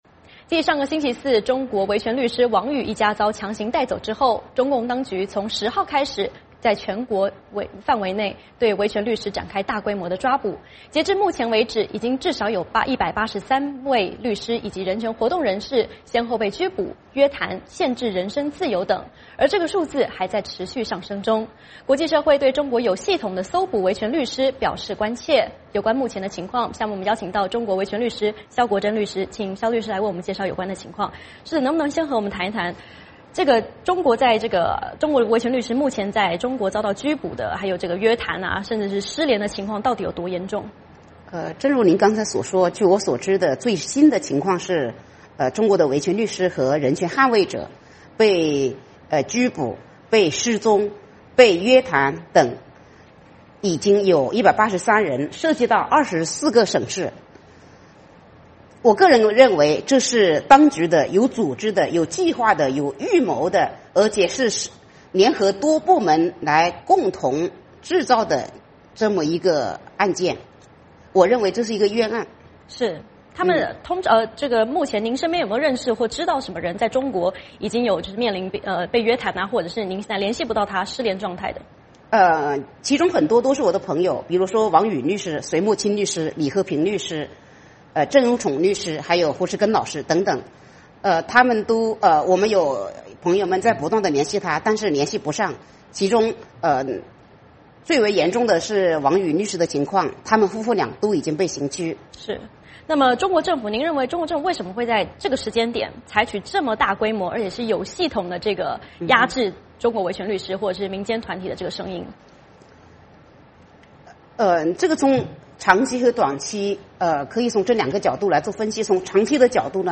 VOA连线：中国维权律师遭捕风波持续 “依法治国”成讽刺？